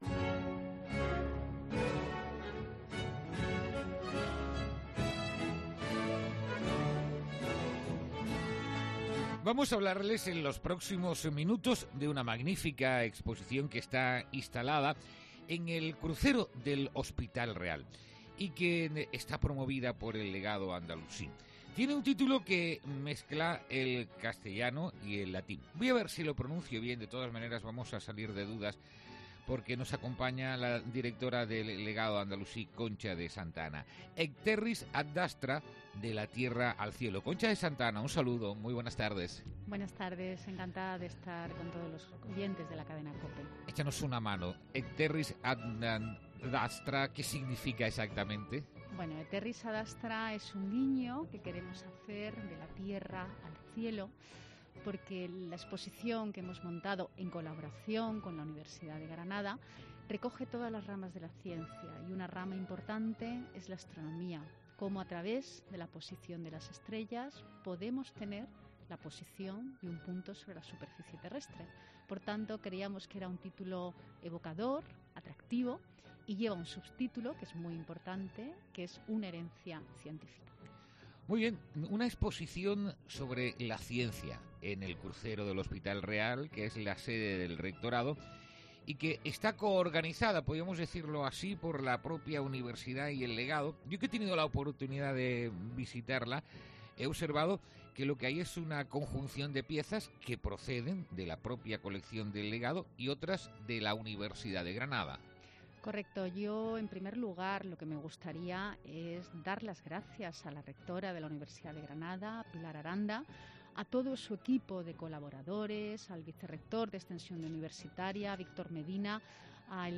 entrevista-en-laCOPE.mp3